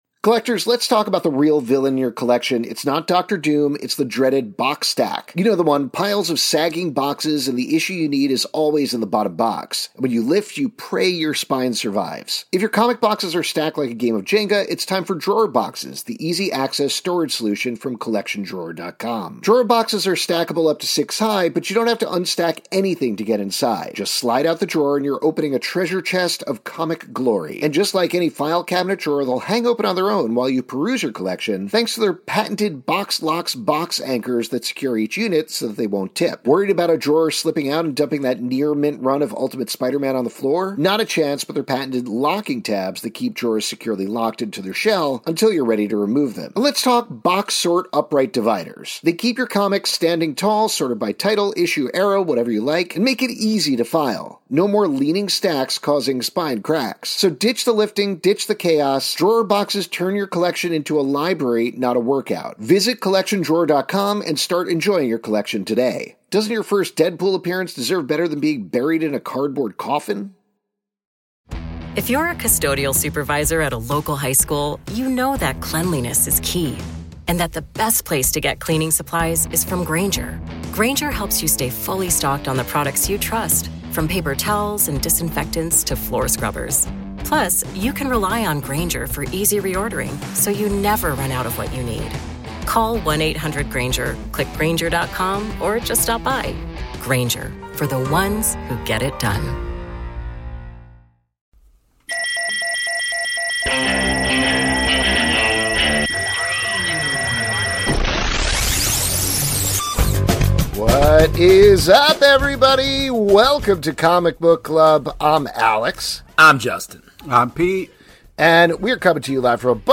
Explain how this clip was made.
On this week's live show